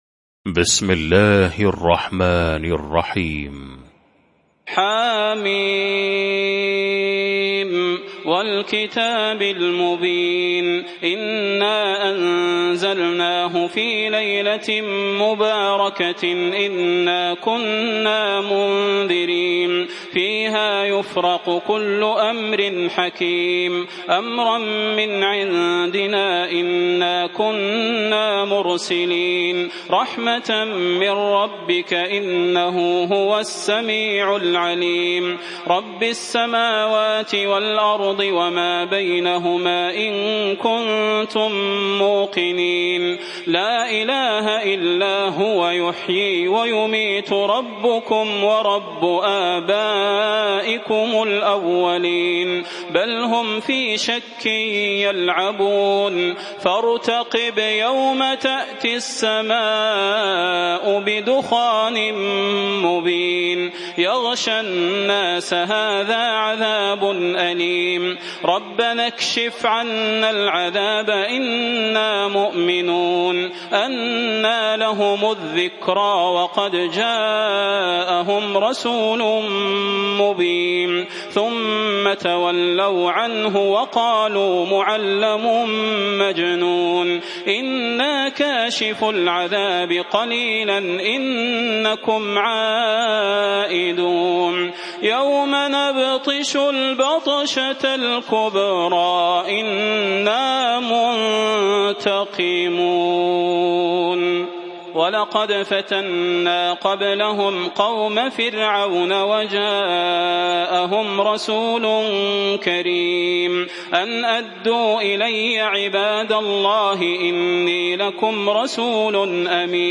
المكان: المسجد النبوي الشيخ: فضيلة الشيخ د. صلاح بن محمد البدير فضيلة الشيخ د. صلاح بن محمد البدير الدخان